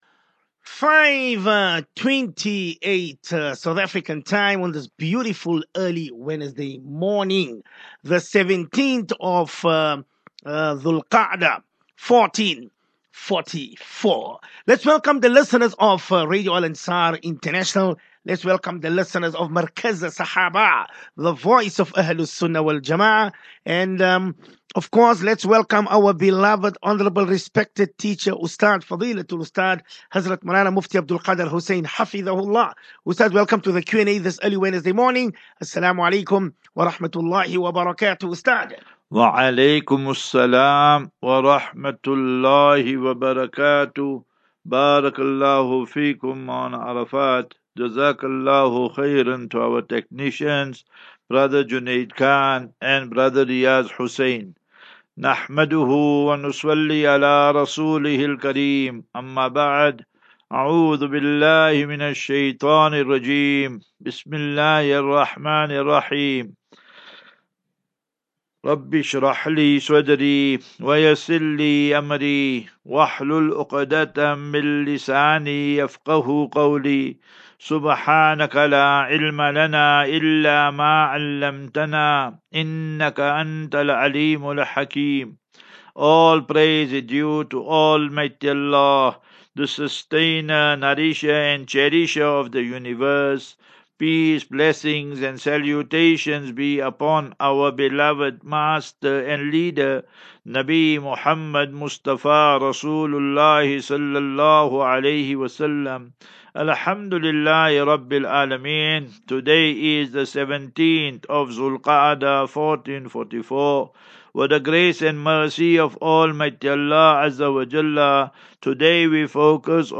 As Safinatu Ilal Jannah Naseeha and Q and A 7 Jun 07 June 23 Assafinatu